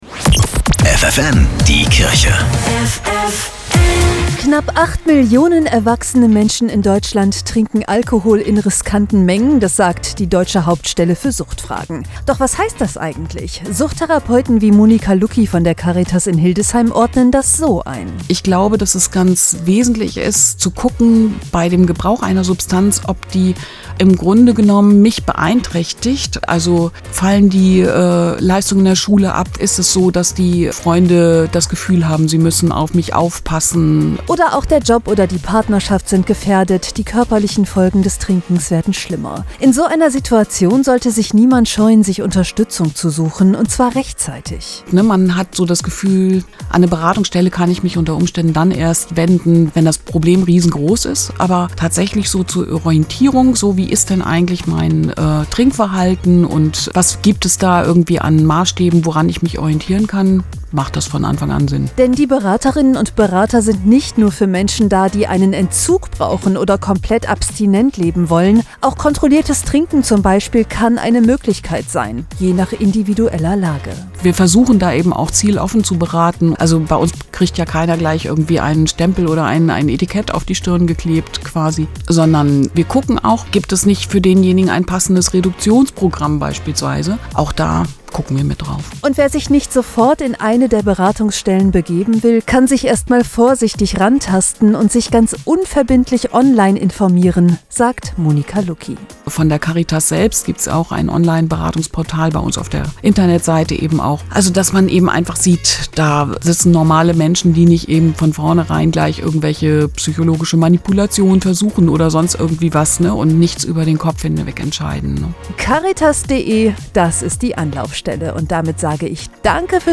Radiobeitrag (Radio ffn) Frauen und Alkohol Teil 3